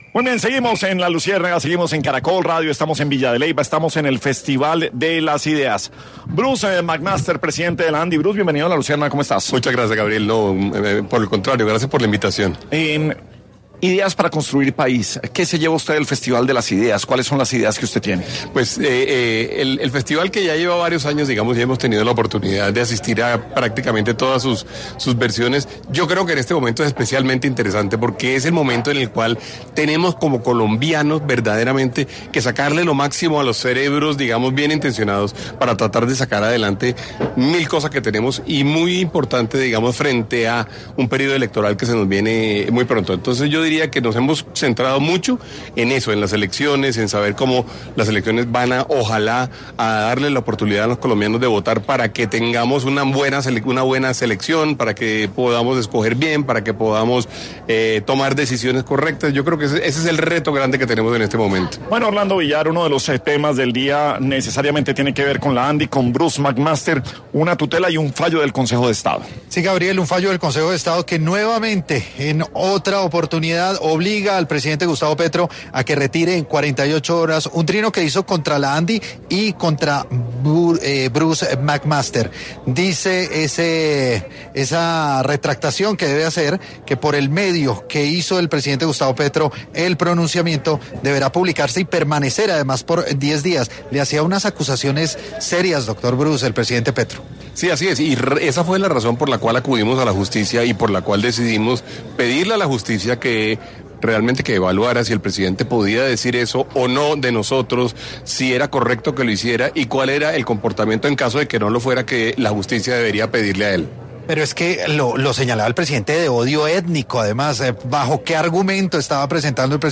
El presidente de la Andi, Bruce Mac Master, estuvo en ‘La Luciérnaga’, desde el ‘Festival de las Ideas’ para hablar de esta decisión que obliga al presidente Gustavo Petro a retractarse.